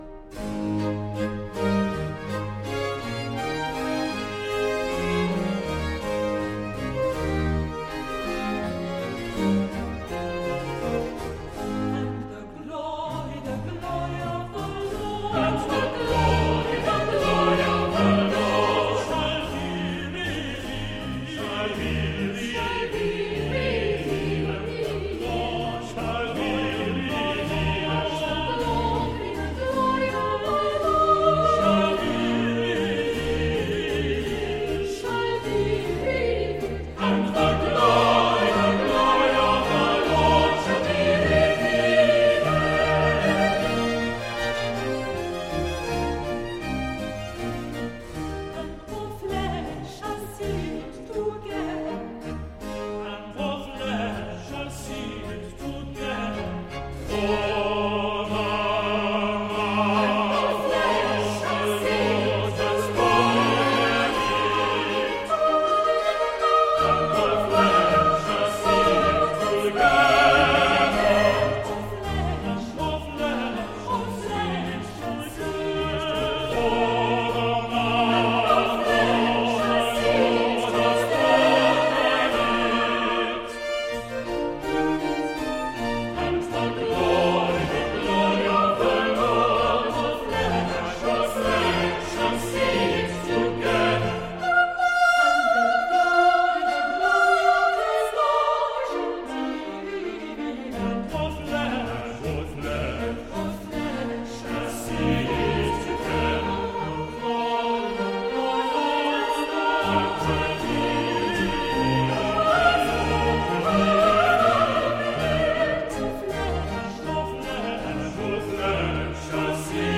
Oratorio
Group: Classical vocal
Handel - Messiah - 04 Chorus _ And The Glory Of The Lord